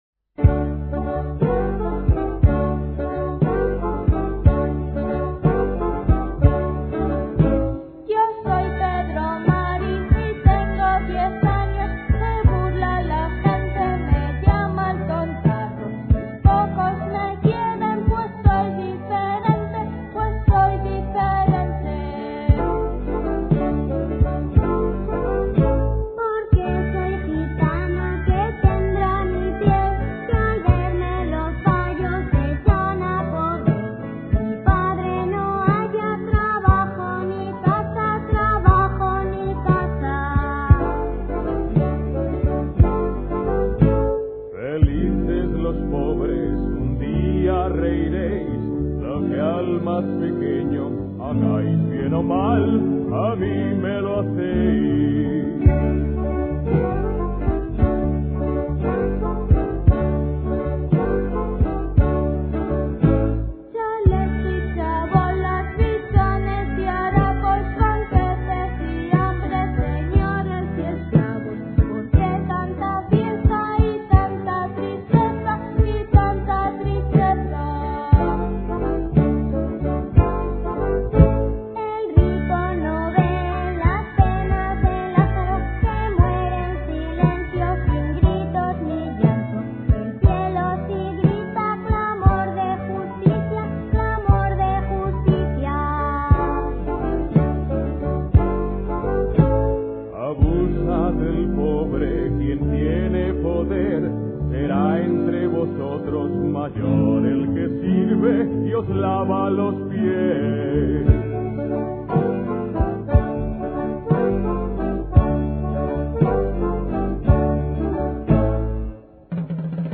Por aquel entonces compuse una canción para la catequesis, a la que puse como título Los pobres de Yavé.